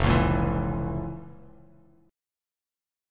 FM7sus4#5 chord